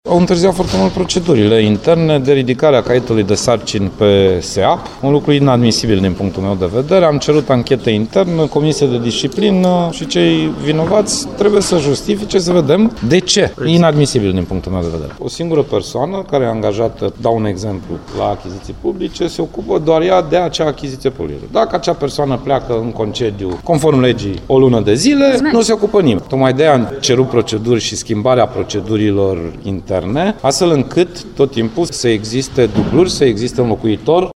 Preşedintele instituţiei, Sorin Grindeanu, spune că subordonaţii săi sunt neperformanţi şi vrea îmbunătăţirea urgentă a situaţiei: